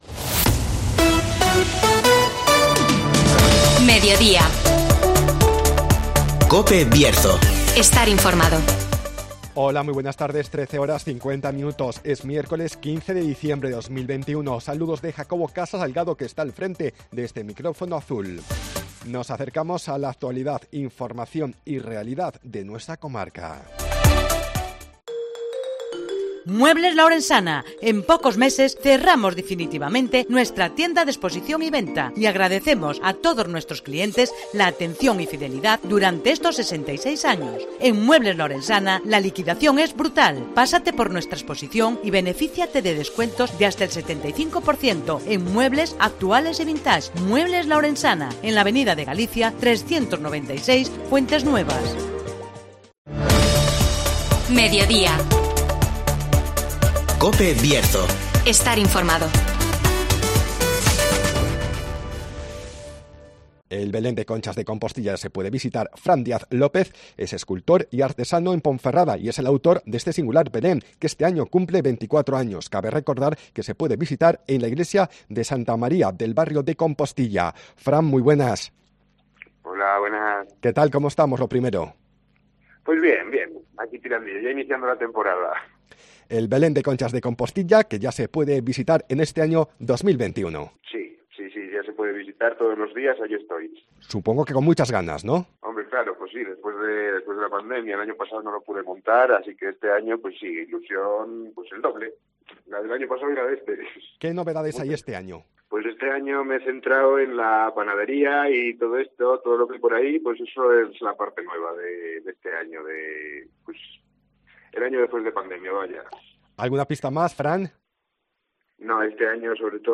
El Belén de Conchas de Compostilla ya se puede visitar (Entrevista